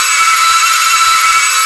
rr3-assets/files/.depot/audio/sfx/transmission_whine/kers_onmid.wav